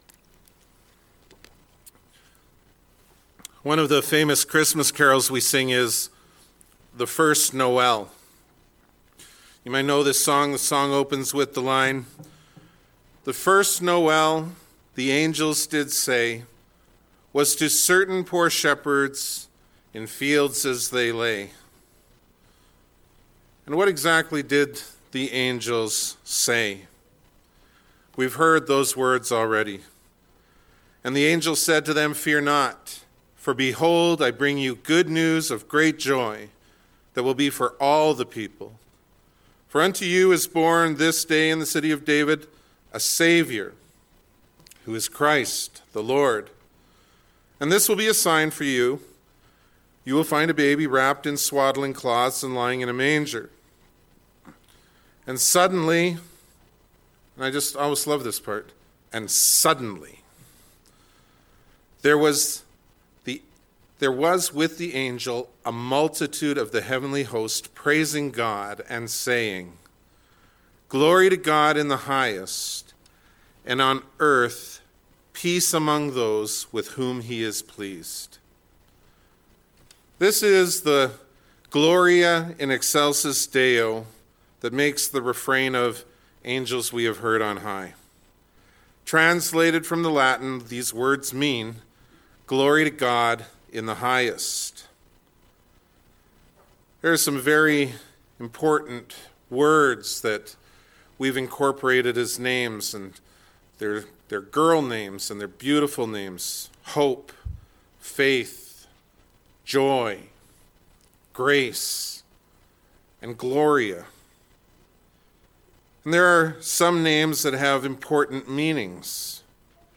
The Christmas Homily – The First Noel